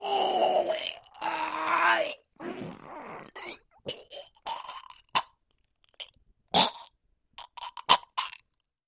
Подборка создана для проектов, где нужна мрачная или тревожная атмосфера.
Звук интоксикации смертельным ядом